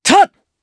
Lucias-Vox_Attack2_jp_b.wav